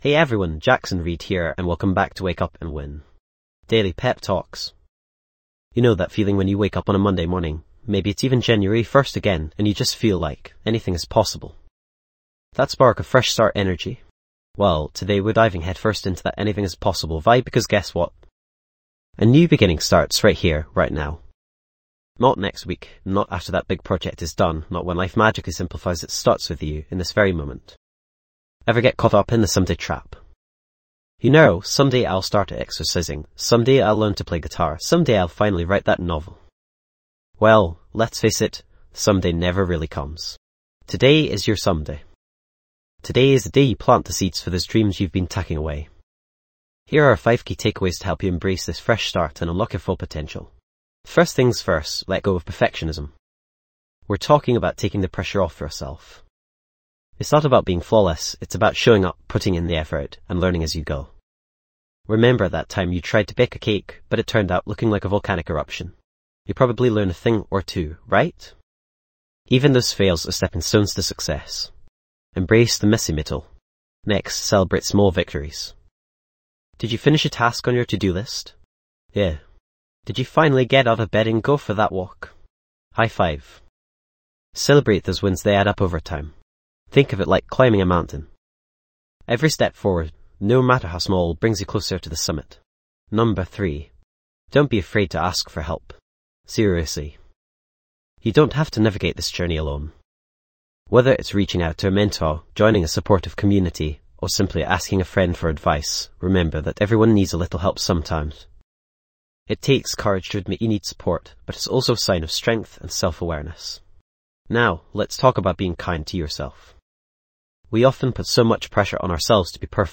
New Beginning:. Daily Pep Talks.
This podcast is created with the help of advanced AI to deliver thoughtful affirmations and positive messages just for you.